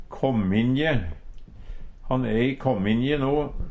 kåmminje - Numedalsmål (en-US)
Fleirtal Eksempel på bruk Han e i kåmminje no. Hør på dette ordet Ordklasse: Adjektiv Attende til søk